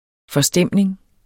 Udtale [ fʌˈsdεmˀneŋ ]